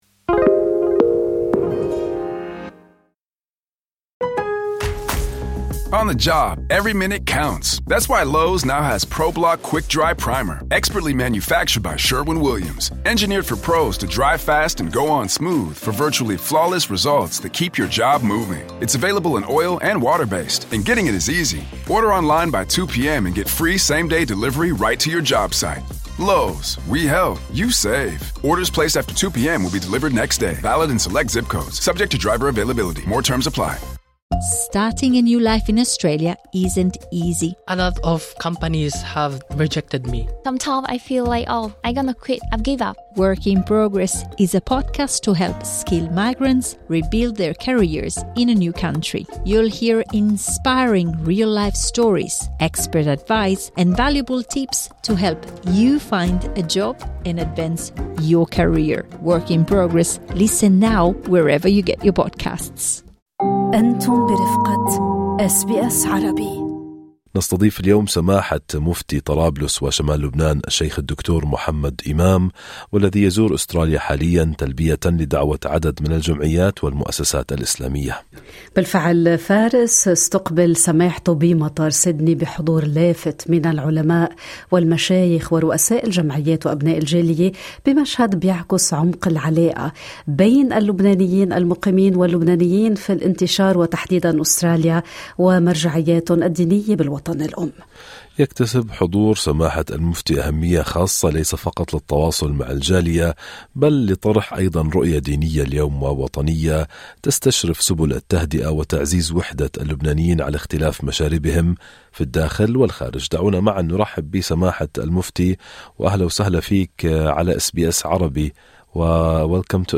كما تترافق هذه الأحداث مع سجالات سياسية حادة في الداخل اللبناني، أبرزها الجدل حول مسألة حصر السلاح بيد الدولة ودور المؤسسات الأمنية، وسط انقسام داخلي وتحديات اقتصادية واجتماعية. استمعوا إلى اللقاء كاملاً في التسجيل الصوتي.
نستضيف اليوم سماحة مفتي طرابلس وشمال لبنان الشيخ الدكتور محمد إمام، والذي يزور أستراليا حالياً تلبيةً لدعوة عدد من الجمعيات والمؤسسات الإسلامية.